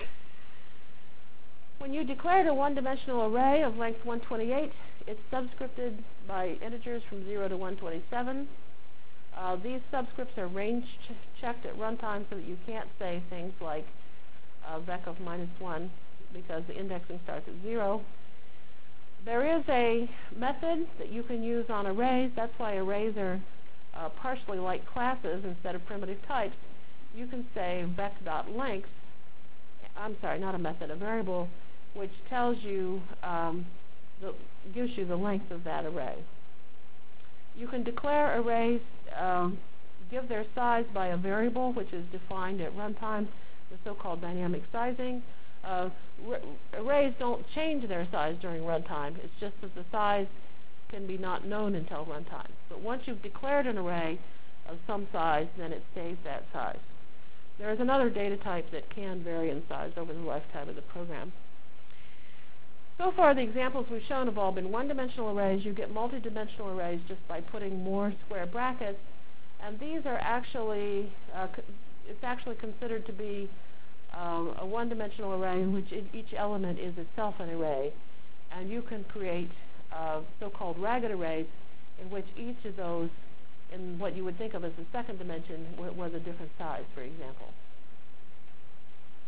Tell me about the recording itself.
From Jan 22 Delivered Lecture for Course CPS616 -- Java Lecture 1 -- Overview CPS616 spring 1997 -- Jan 22 1997.